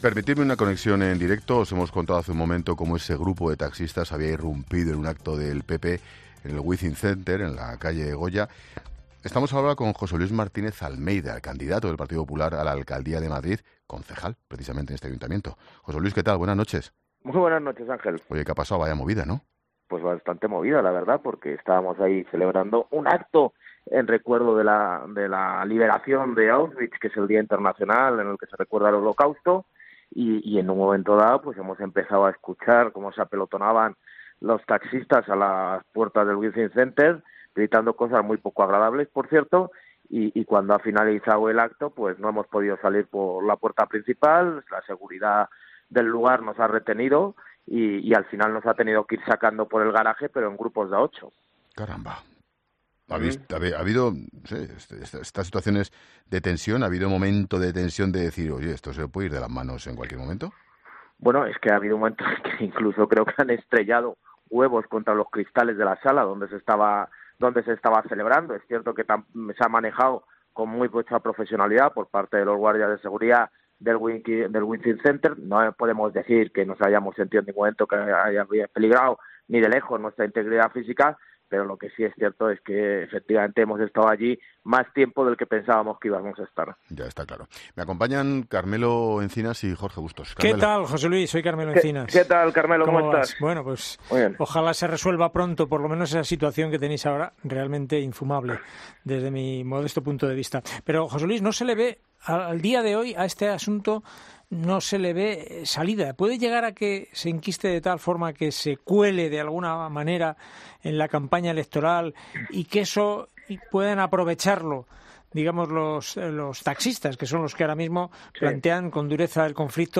En los micrófonos de La Linterna de COPE, Martínez-Almeida ha relatado como han tenido que “abandonar el acto por la puerta del garaje” mientras los taxistas allí concentrados les grataban cosas “poco agradables” y les tiraban “huevos a las ventanas”, ha contado el candidato de los populares.